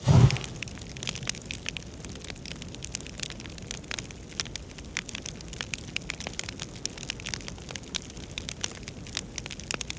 Light Torch with Starting Loop 1.ogg